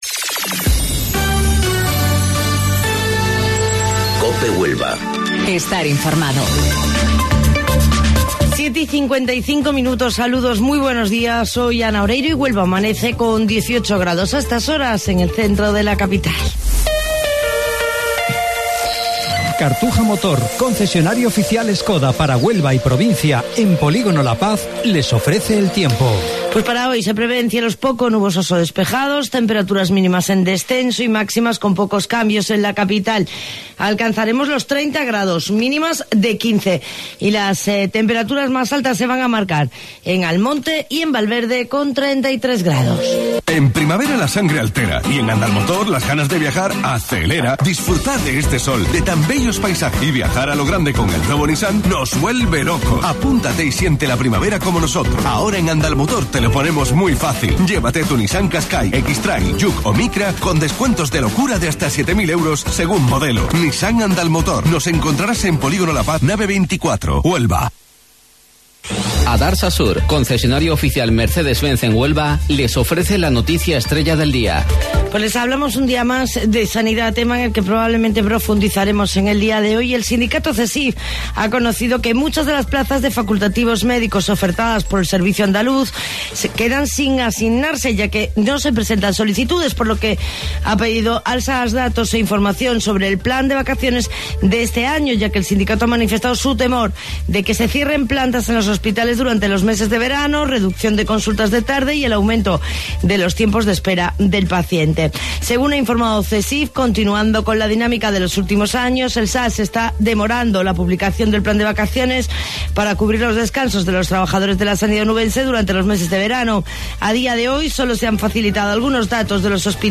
AUDIO: Informativo Local 07:55 del 30 de Mayo